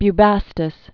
(by-băstĭs)